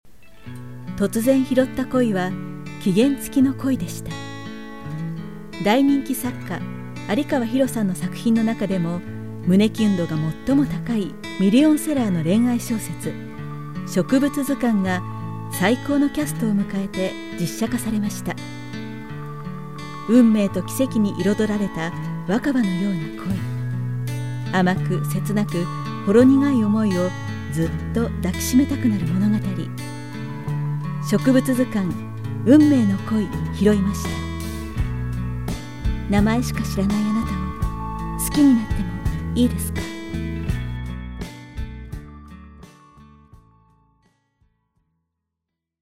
narration_06.mp3